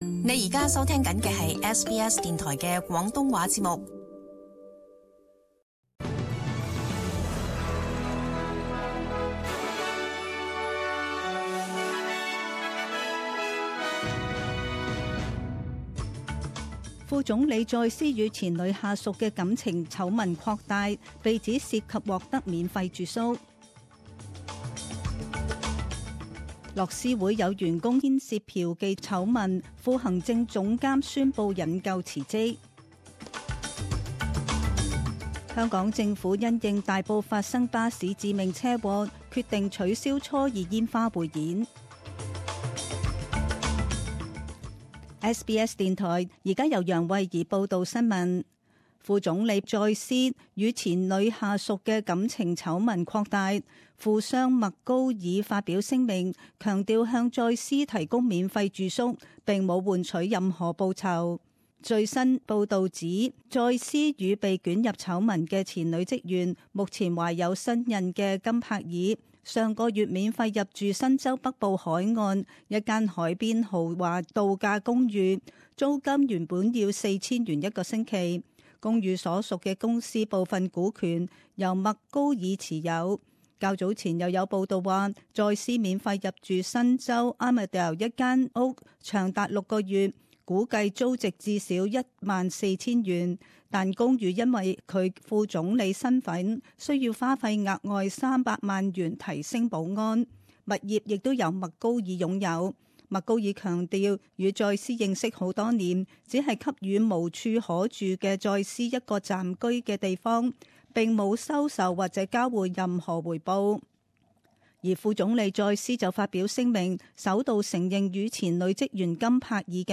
詳盡早晨新聞